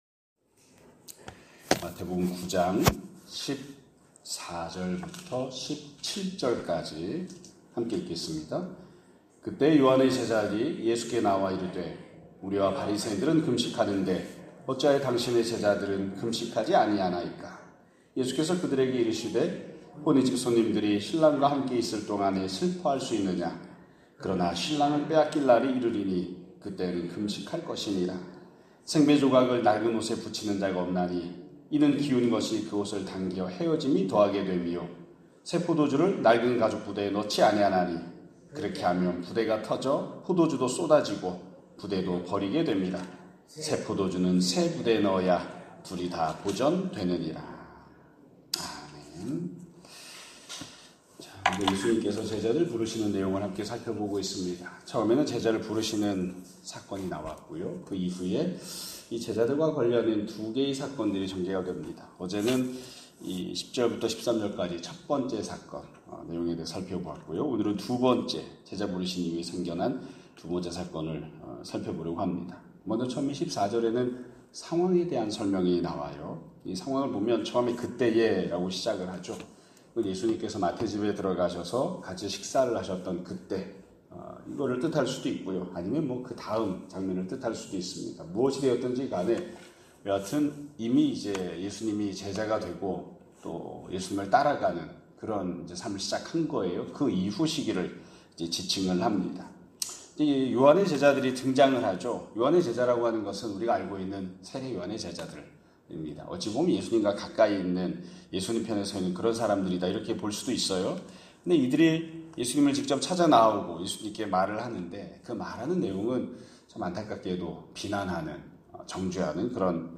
2025년 7월 22일(화요일) <아침예배> 설교입니다.